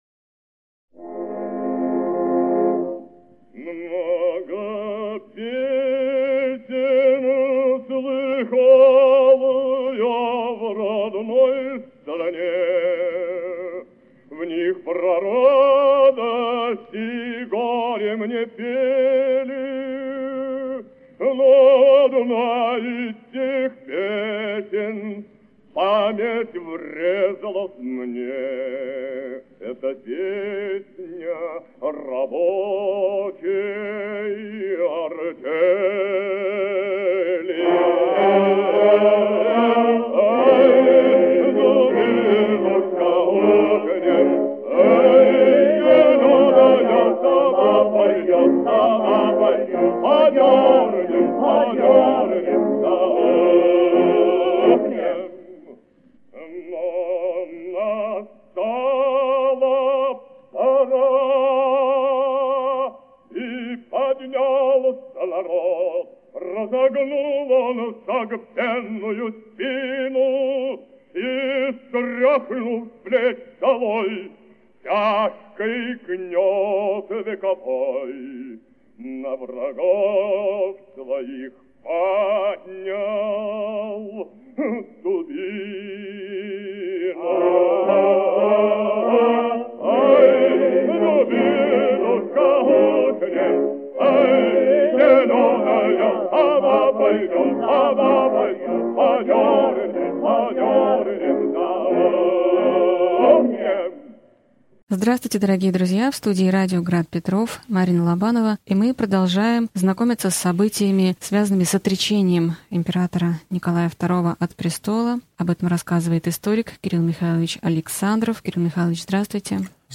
Аудиокнига Февральская революция и отречение Николая II. Лекция 34 | Библиотека аудиокниг